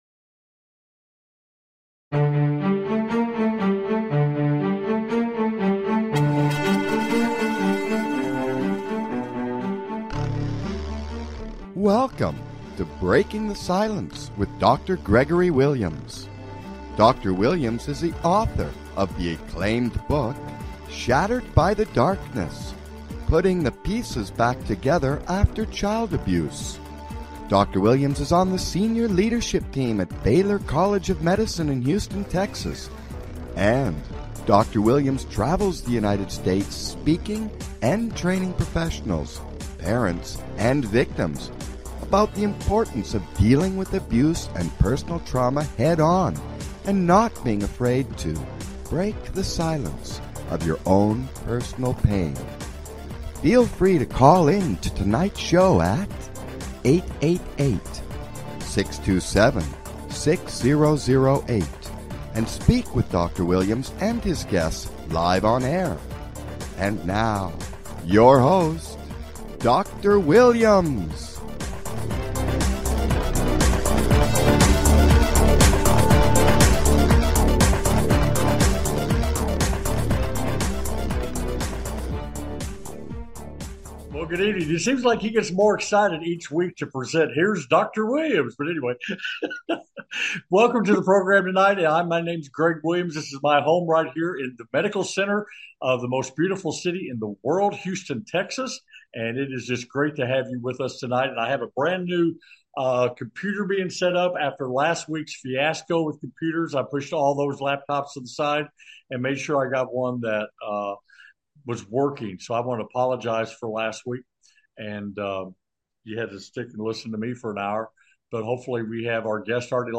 With Guest